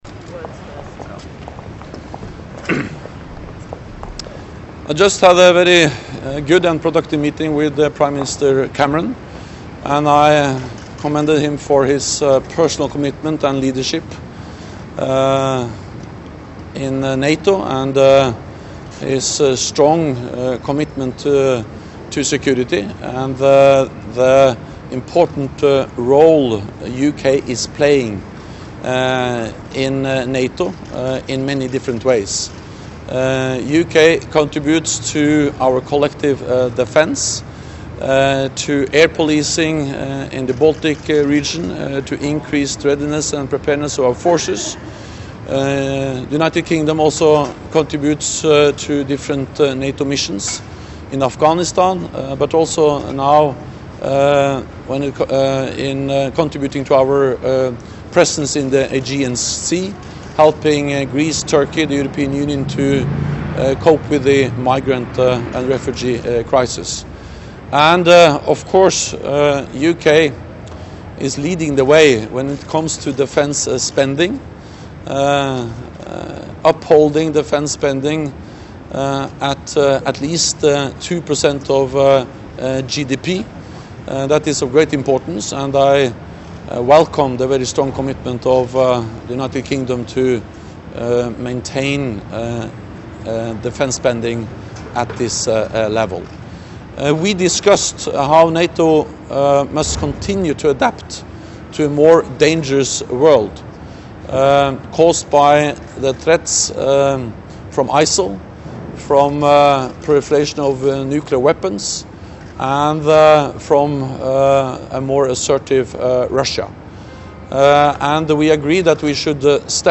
Opening statement by NATO Secretary General Jens Stoltenberg at the joint press point with UK Prime Minister, David Cameron
(As delivered)